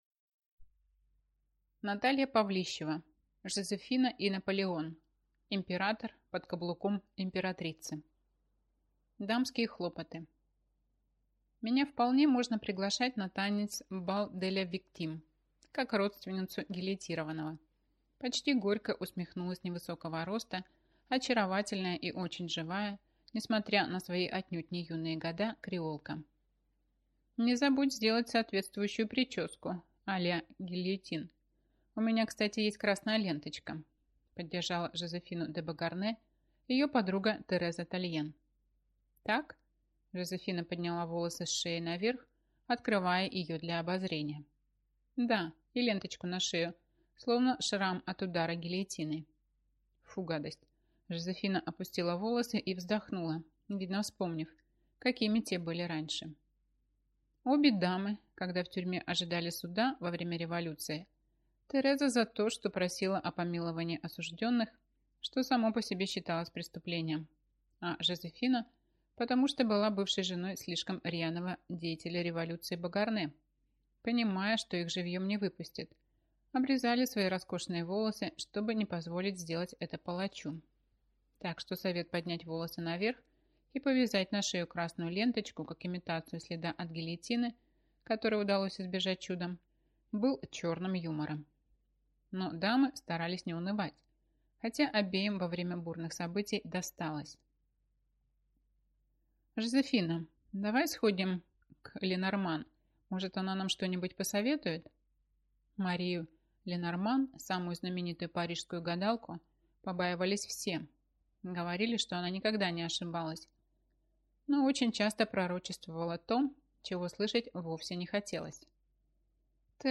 Аудиокнига Жозефина и Наполеон. Император «под каблуком» Императрицы | Библиотека аудиокниг